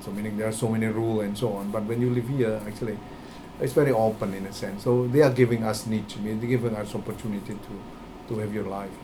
S1 = Taiwanese female S2 = Indonesian male Context: S2 is talking about living in Brunei.
First, it is pronounced as [nɪtʃ] rather than the expected [ni:ʃ] .